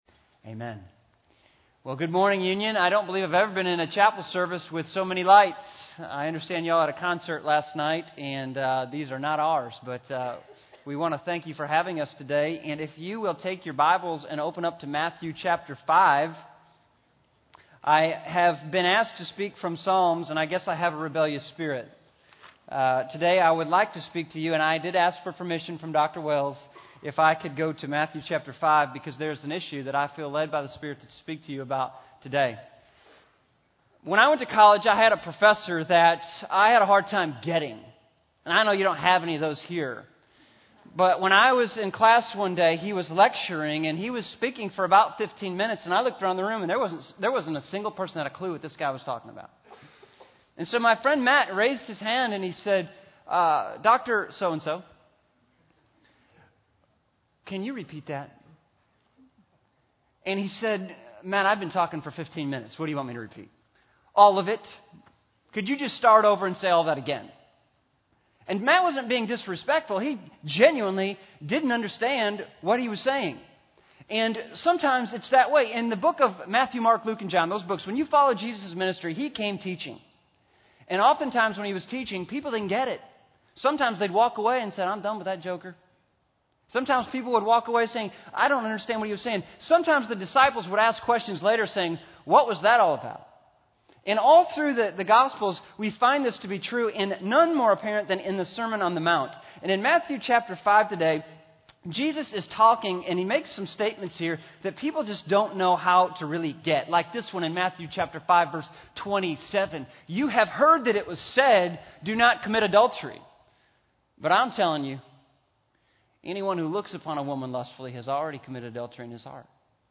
Address: Can You Repeat That? The Hard Sayings of Jesus Recording Date: Apr 29, 2009, 10:00 a.m. Length: 23:27 Format(s): MP3 ; Listen Now Chapels Podcast Subscribe via XML